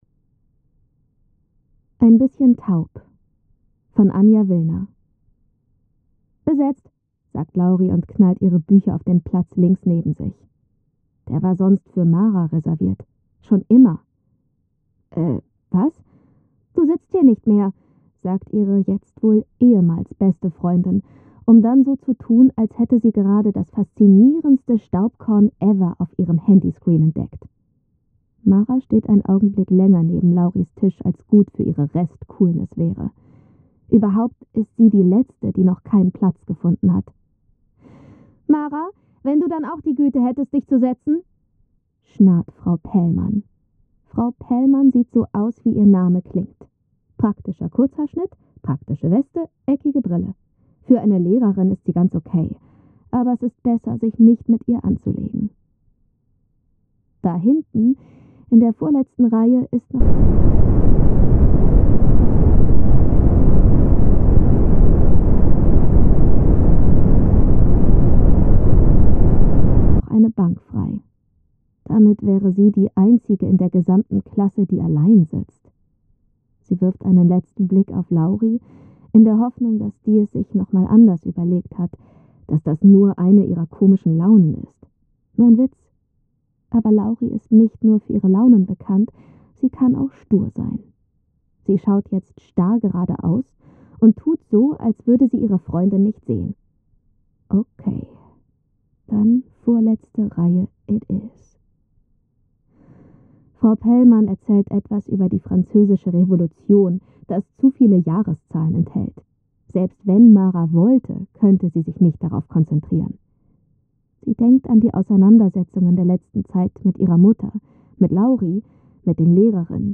Anschließend hören die Schülerinnen und Schüler den Anfang der Kurzgeschichte in einer bewusst veränderten Audiofassung, die eine Hörminderung simuliert. So erhalten sie einen realitätsnahen Eindruck eingeschränkter Hörwahrnehmung und werden für das Thema sensibilisiert.
kommunikation-in-kurzgeschichten-textgrundlage-hoerfassung_01.mp3